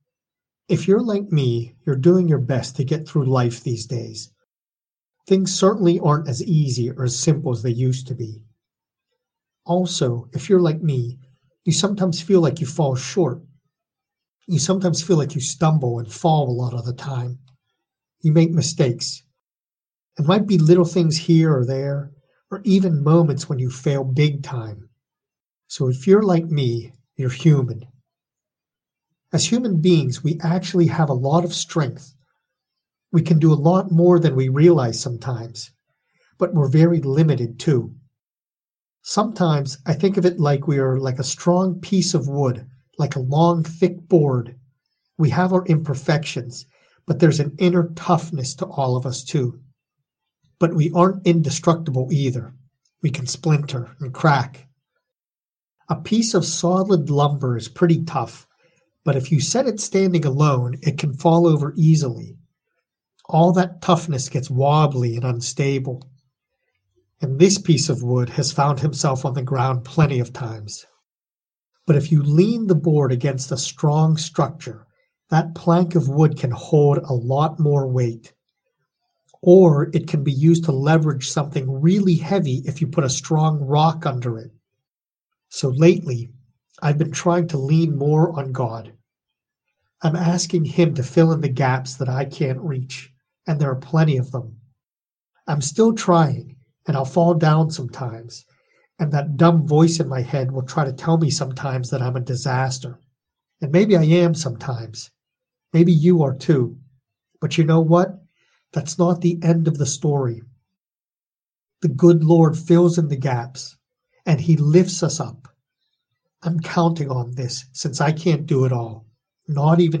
prayer-for-god-to-fill-in-the-gaps.mp3